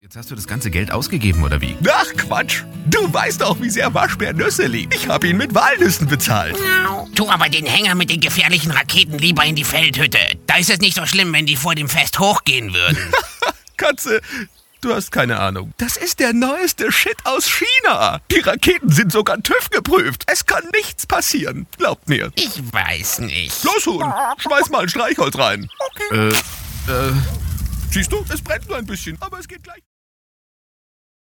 Sprachproben
Trickstimmen